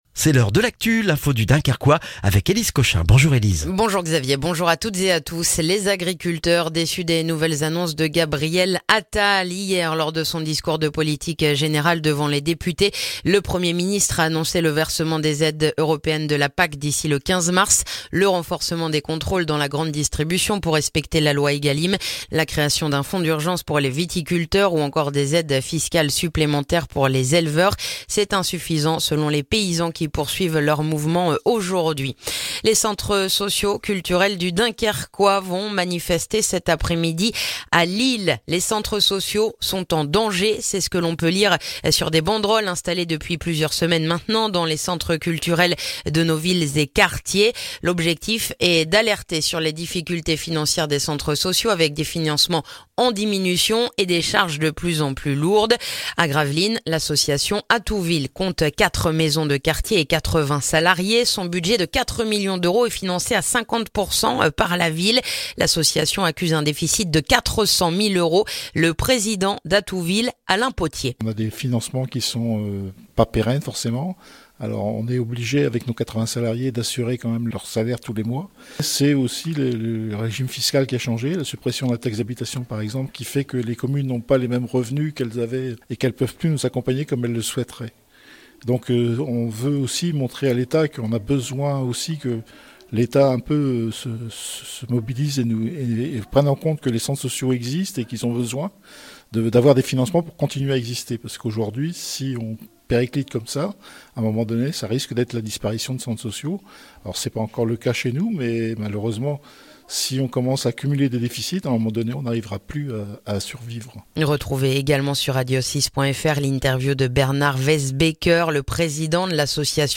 Le journal du mercredi 31 janvier dans le dunkerquois